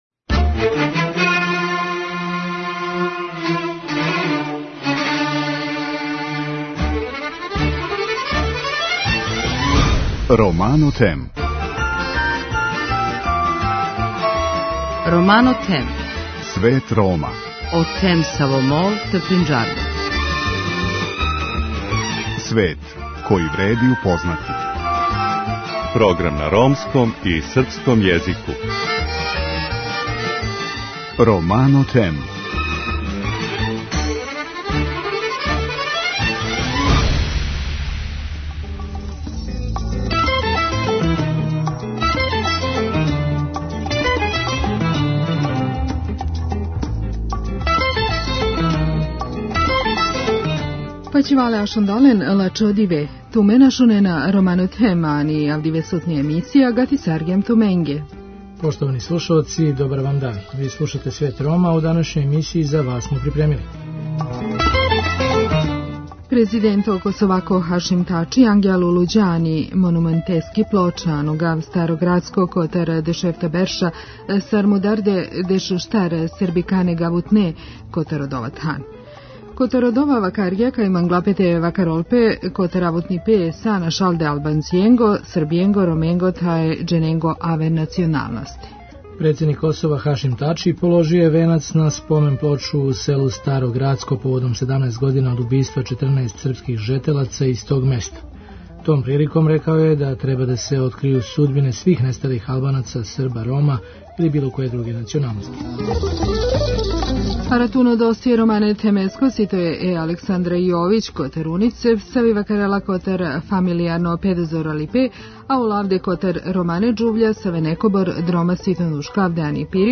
преузми : 7.20 MB Romano Them Autor: Ромска редакција Емисија свакодневно доноси најважније вести из земље и света на ромском и српском језику. Бави се темама из живота Рома, приказујући напоре и мере које се предузимају за еманципацију и интеграцију ове, највеће европске мањинске заједнице.